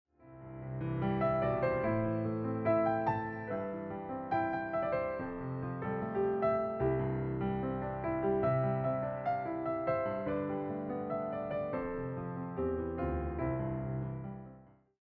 interpreted through solo piano.